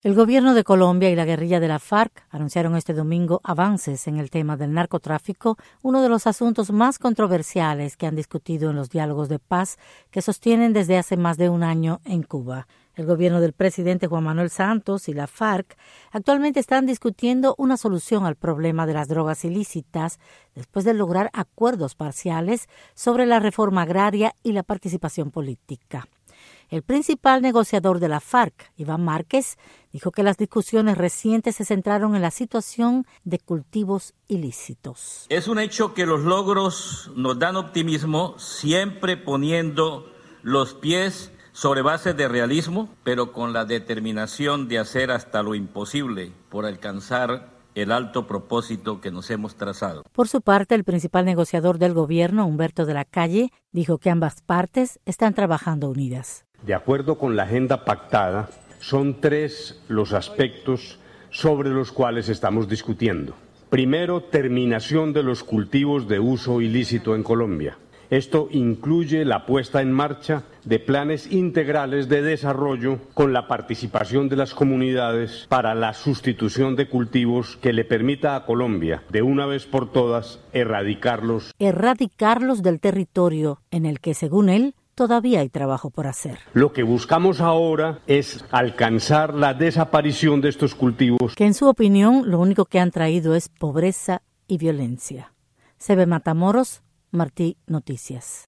Reportaje sobre conversaciones de paz entre guerrilla y Gobierno